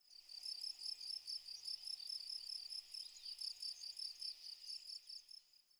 Cricket 2.wav